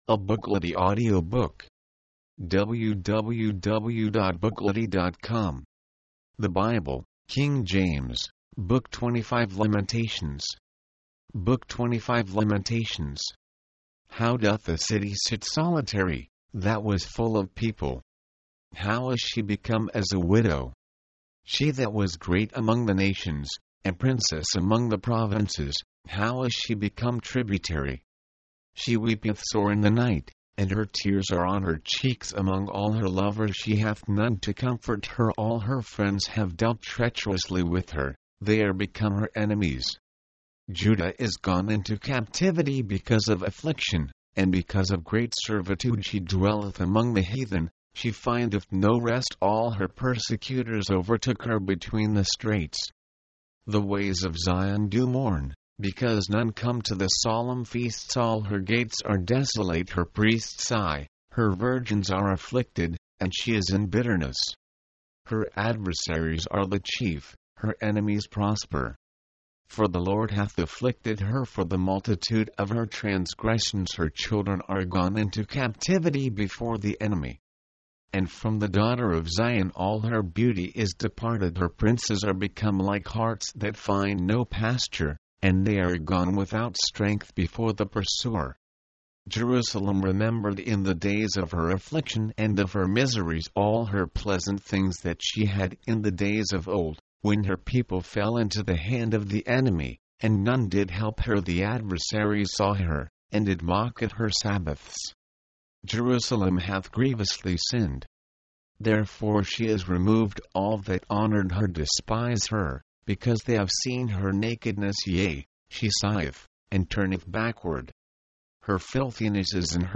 This book records five "laments" for the fallen city. mp3, audiobook, audio, book Date Added: Dec/31/1969 Rating: Add your review